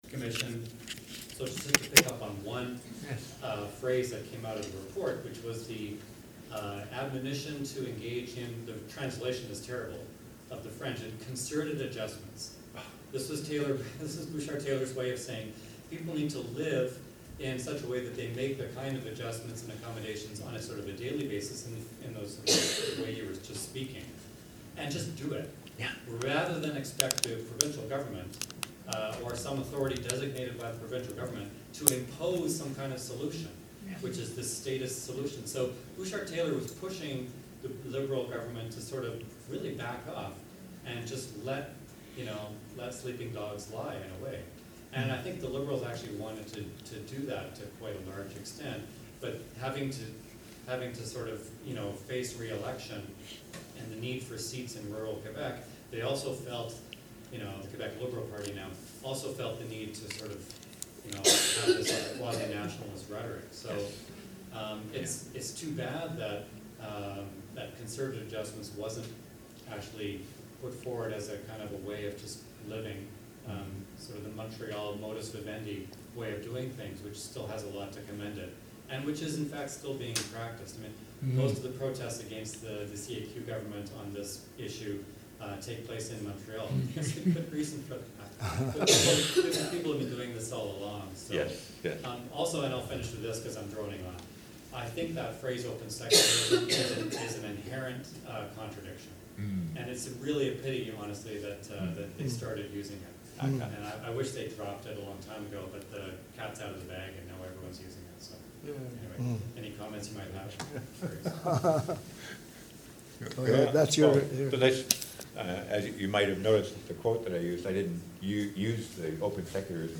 q-a-taylor-on-identity.mp3